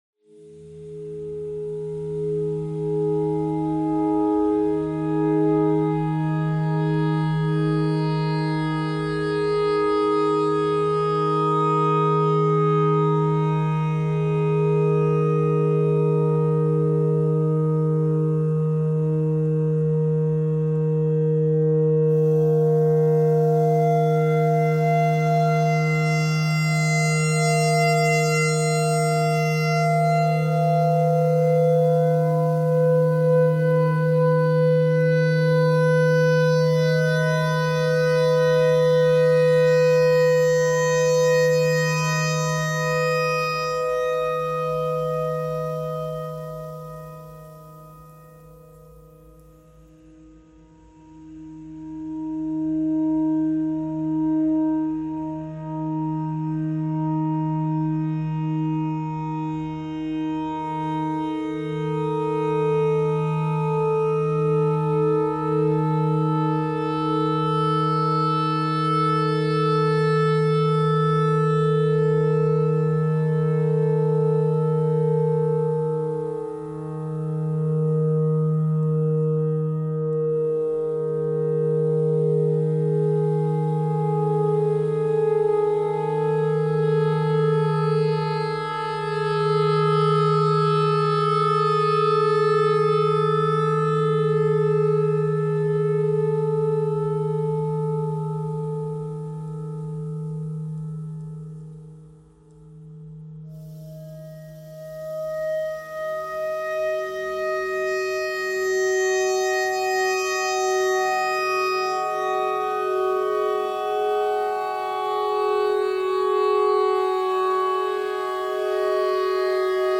::: an exercise in spinning thread from recorded sound fragments & then weaving these individual strands into twisted cords ::: an exercise in chance-infused, text-based, generative composition.
clarinetnotes_e_clarinetnotes_e_thread_noise_all.mp3